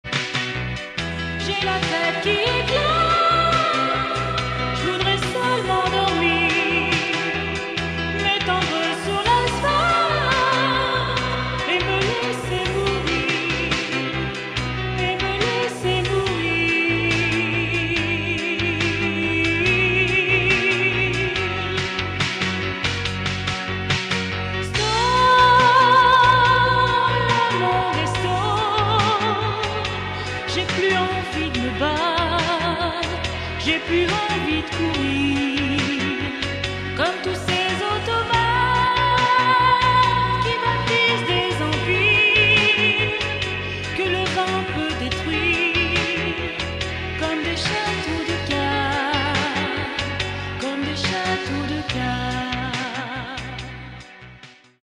EXTRAIT SLOWS